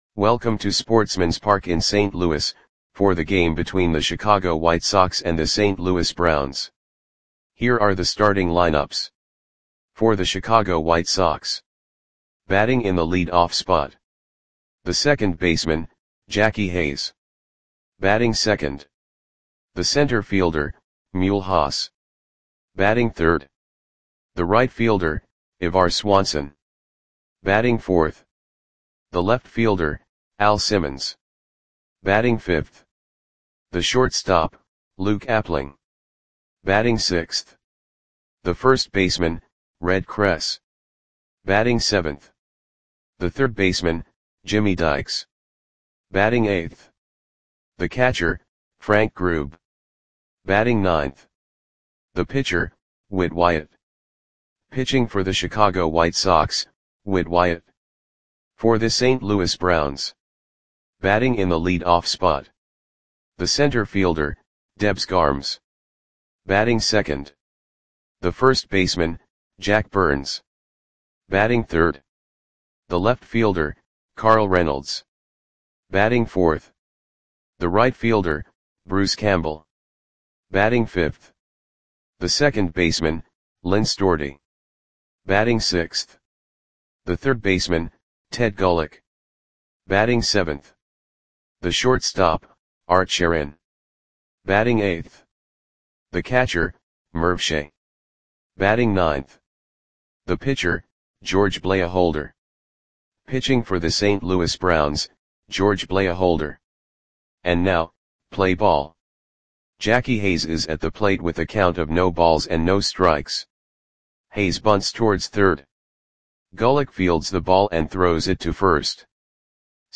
Audio Play-by-Play for St. Louis Browns on June 5, 1933
Click the button below to listen to the audio play-by-play.